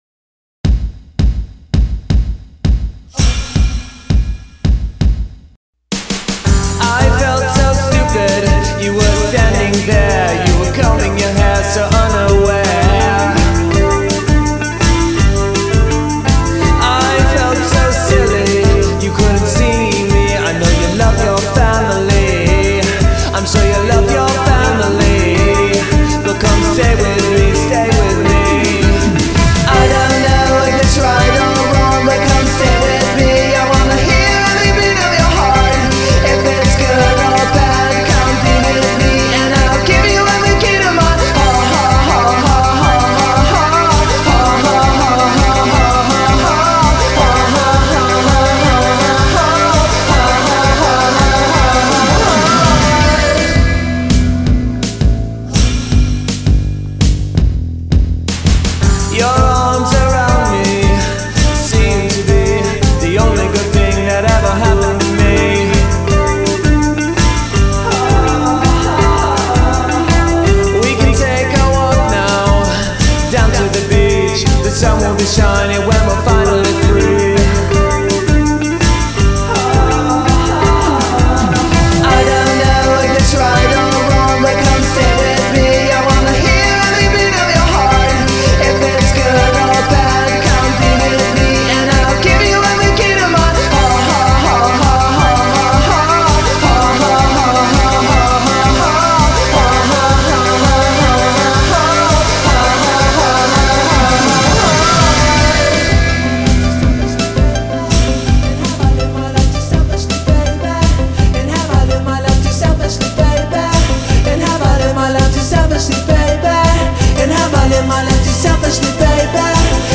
guitar
back-up singers